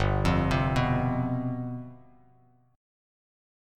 Listen to G11 strummed